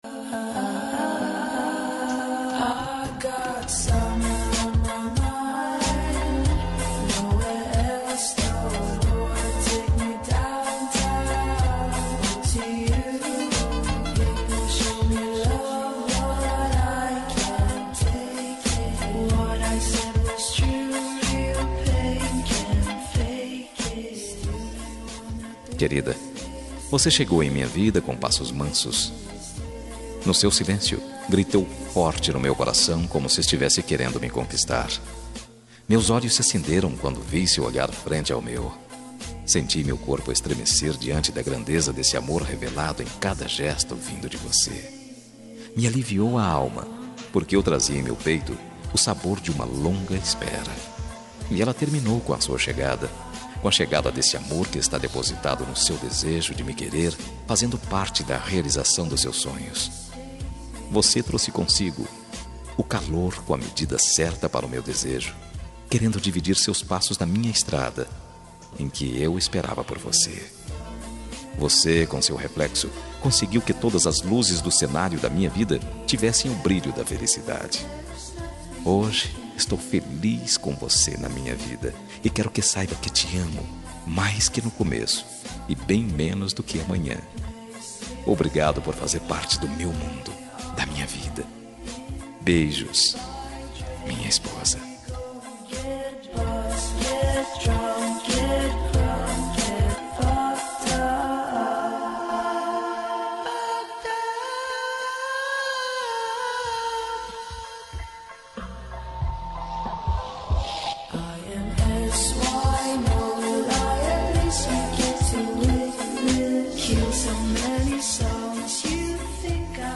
Romântica para Esposa – Voz Masculina – Cód: 6725